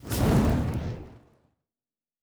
pgs/Assets/Audio/Sci-Fi Sounds/Weapons/Weapon 14 Shoot 1 (Flamethrower).wav at master
Weapon 14 Shoot 1 (Flamethrower).wav